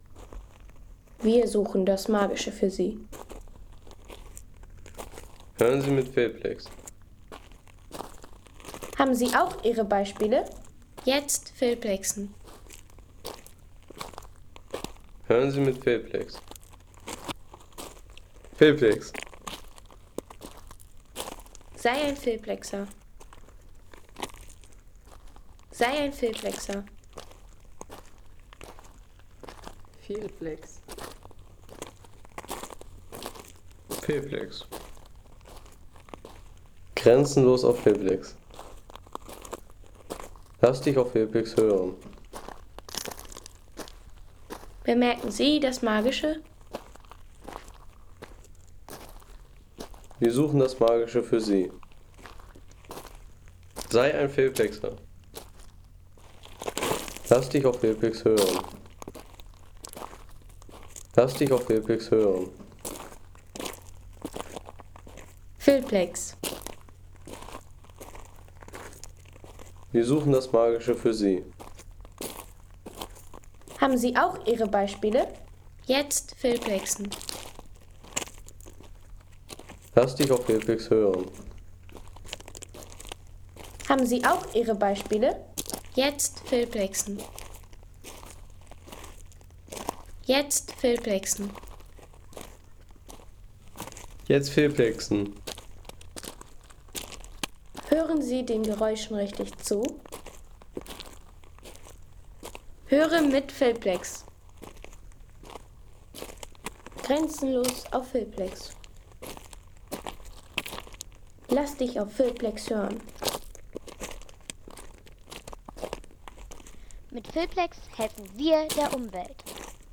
Pebbles